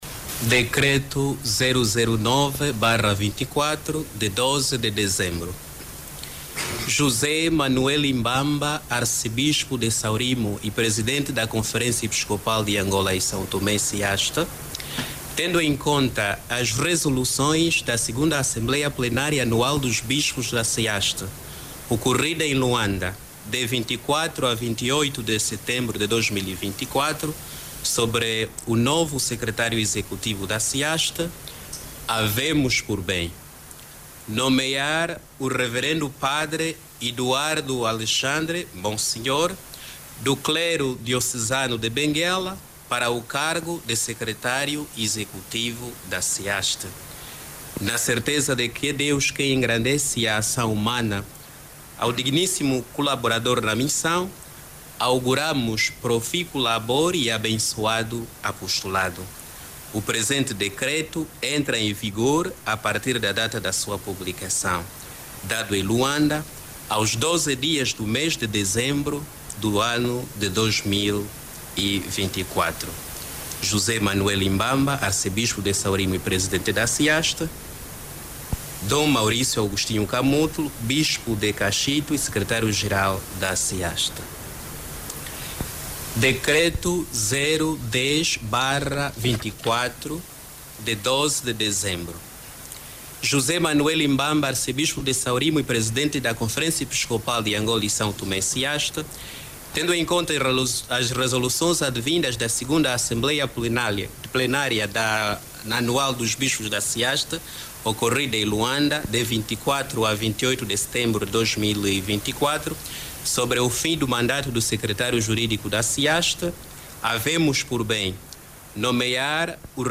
Os decretos foram lidos na manha de hoje durante a missa matinal decorrida na capela da CEAST.